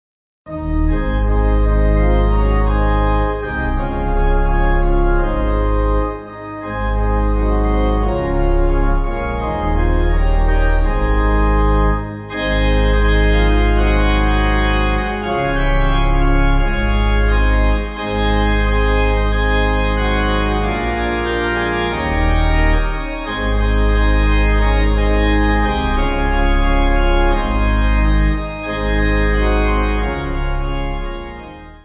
Organ
(CM)   3/Ab